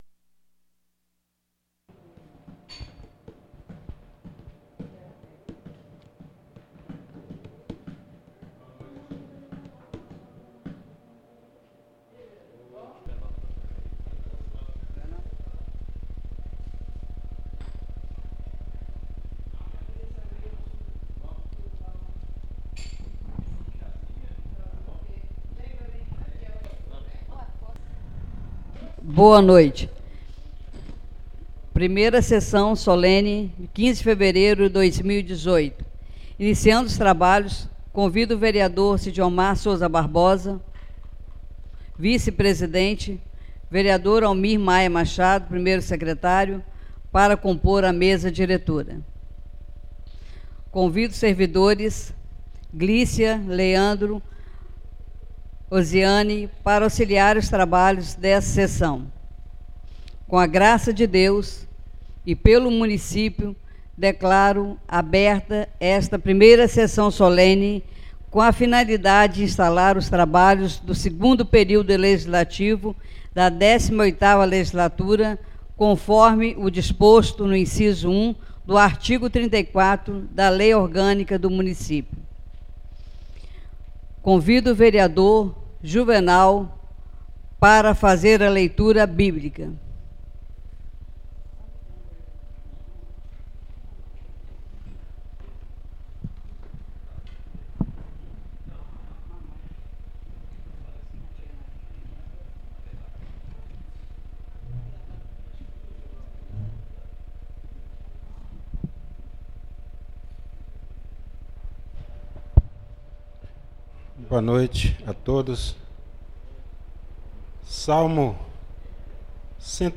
Sessão Solene inauguração da legislatura